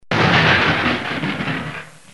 جلوه های صوتی
دانلود صدای بمب و موشک 22 از ساعد نیوز با لینک مستقیم و کیفیت بالا